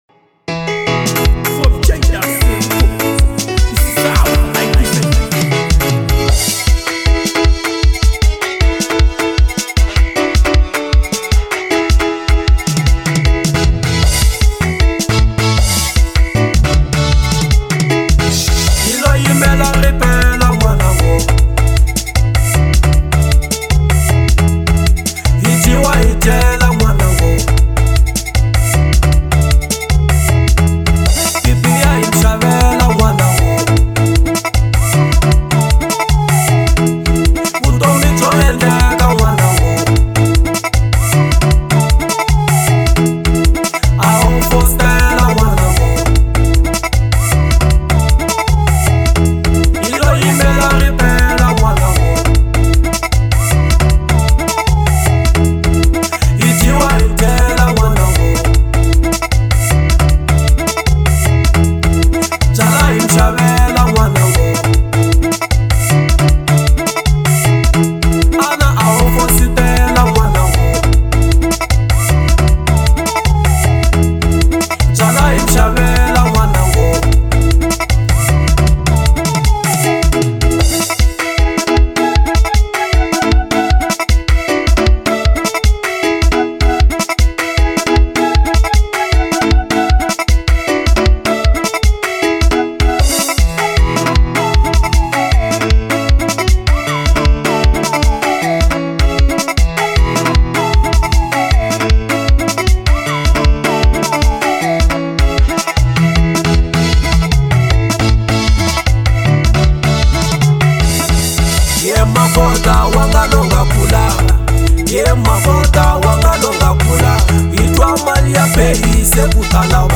03:56 Genre : Xitsonga Size